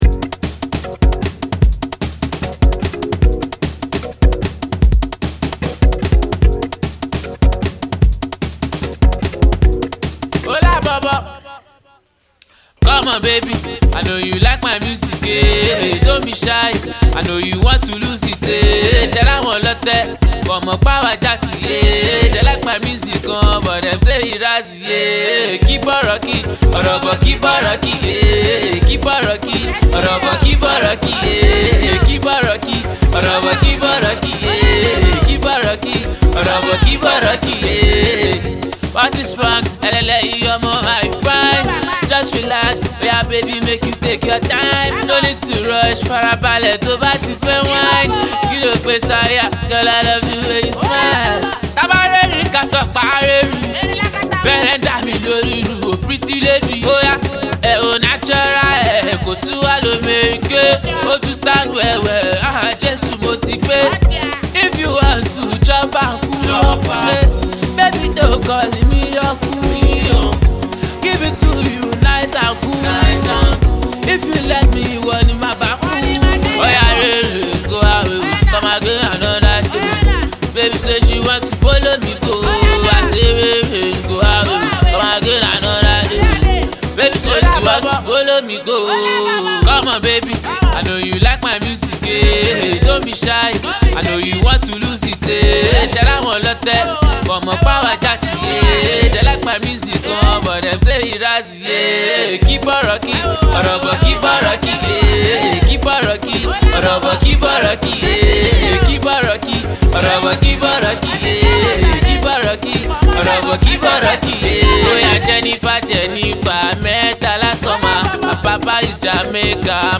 Fuji rendition